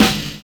N.Y RAP    8.wav